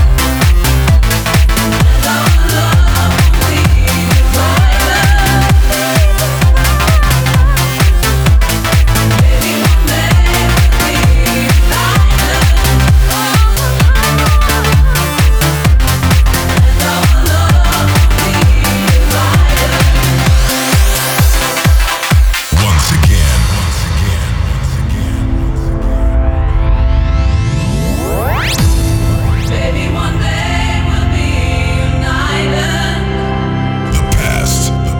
Жанр: Танцевальные / Поп / Электроника